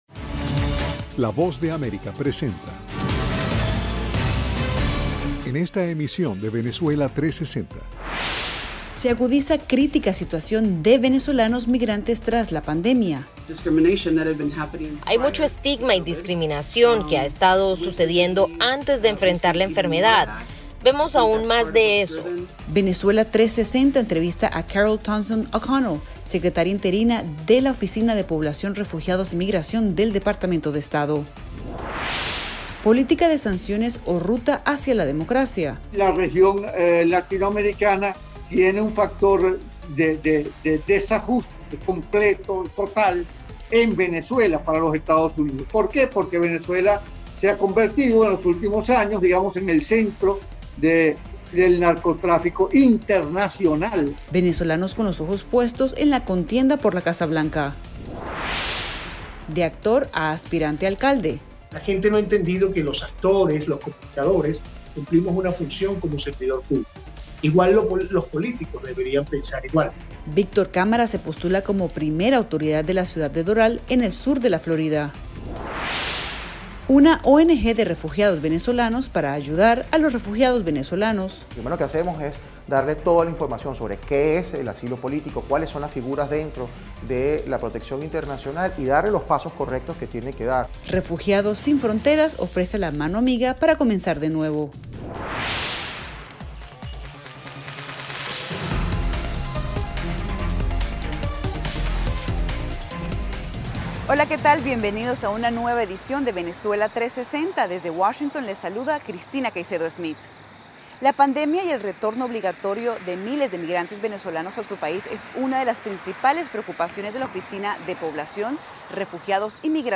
En esta nueva edición de Venezuela 360, entrevista con Carol Thompson O’Connell, secretaria interina la Oficina de Población, Refugiados y Migración del Departamento de Estado, para conocer la crítica situación de los venezolanos que están retornando a su país. Un informe especial sobre donde está el tema de “Venezuela” en la campaña por la Casa Blanca y de actor a político, entrevista con Víctor Cámara.